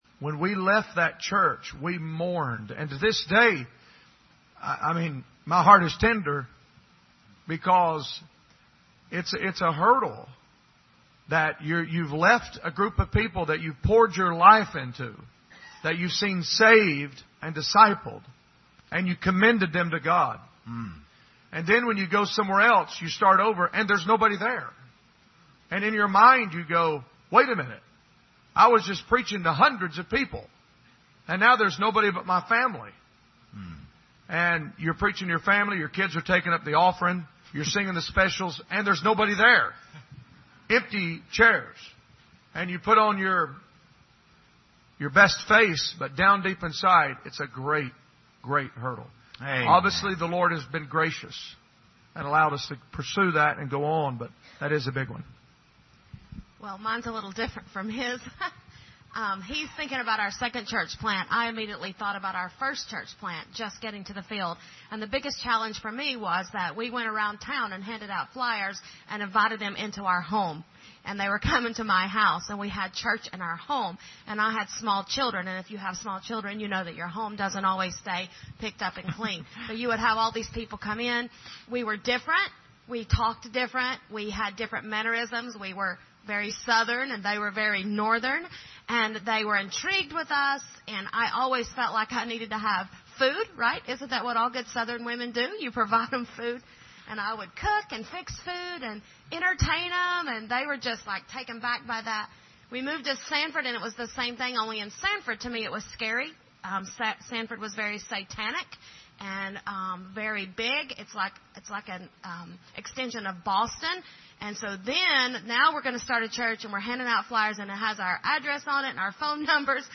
Missions Conference Panel Discussion
Service Type: Special Service